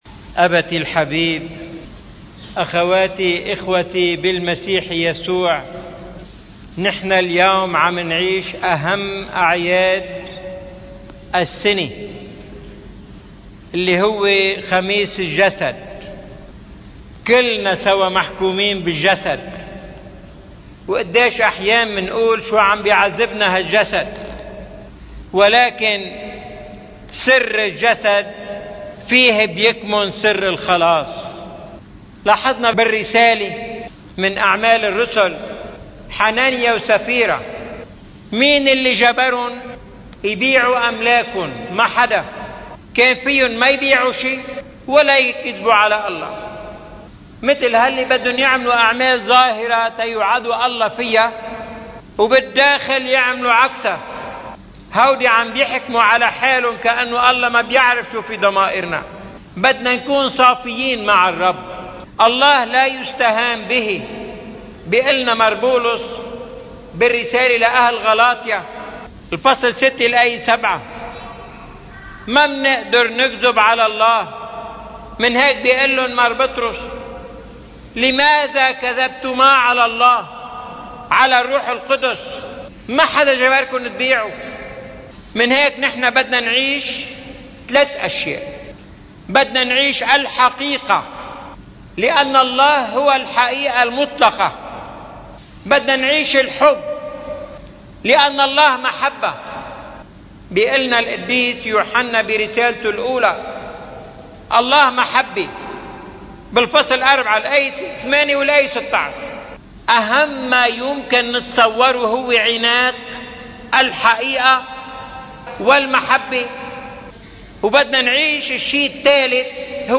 عظة المطران الياس سليمان في دمشق – باب توما: (خميس الجسد 31 أيار 2018)